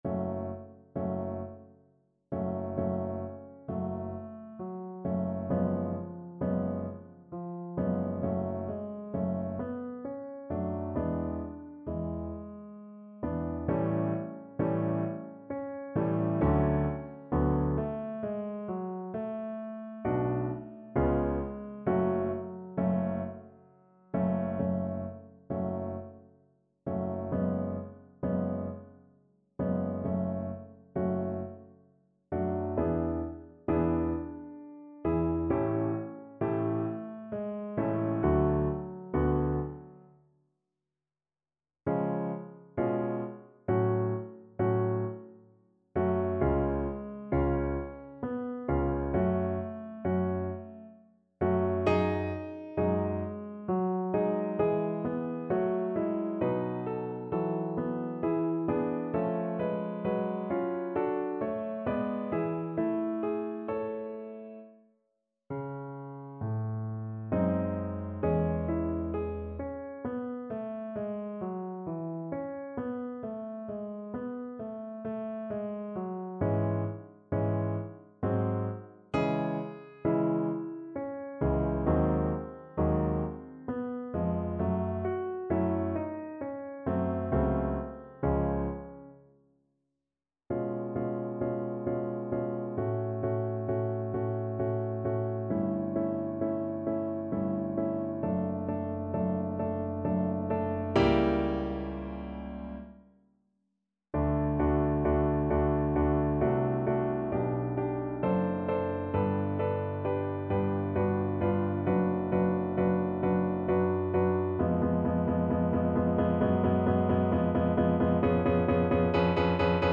3/4 (View more 3/4 Music)
~ = 100 Adagio =66
Classical (View more Classical Bass Voice Music)